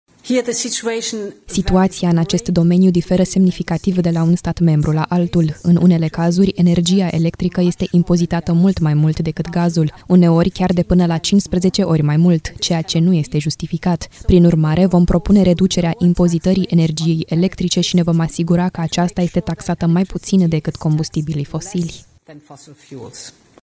20mar-12-Ursula-taxele-si-impozitele-TRADUS.mp3